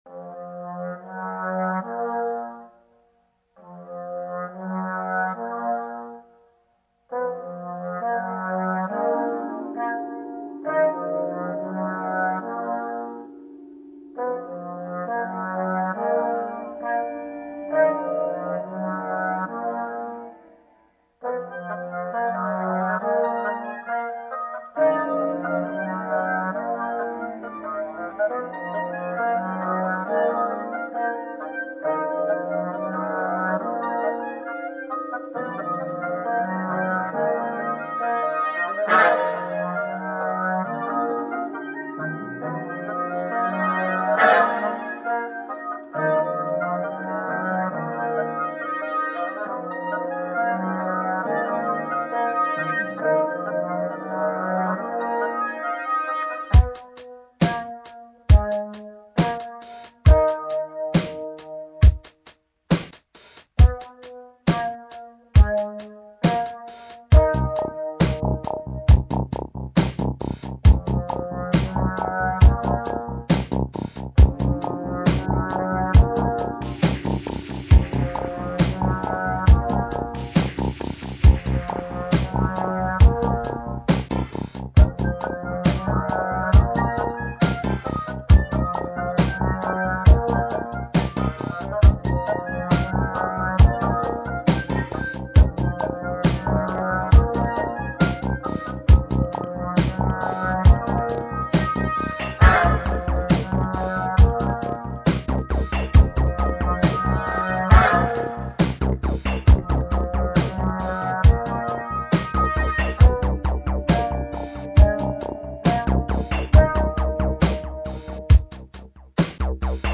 Think Orchestral electronica.